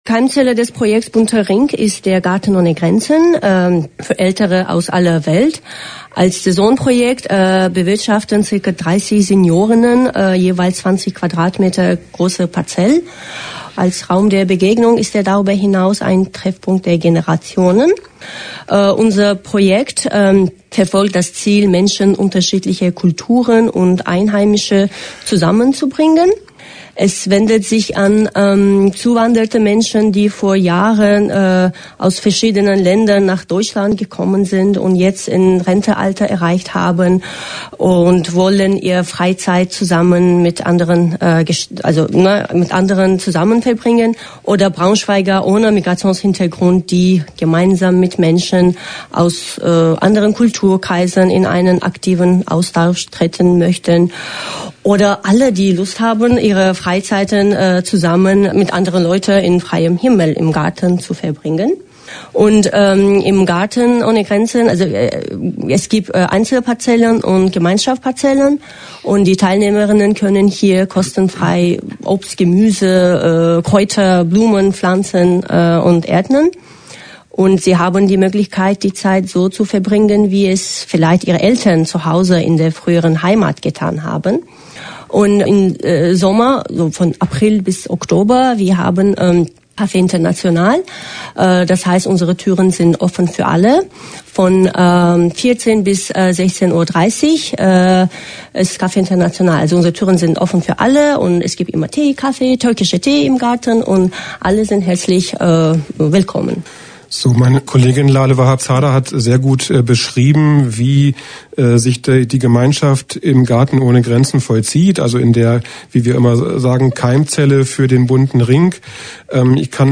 Interview-Bunter-Ring.mp3